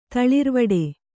♪ taḷirvaḍe